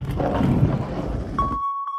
Zipper
Zipper is a free foley sound effect available for download in MP3 format.
067_zipper.mp3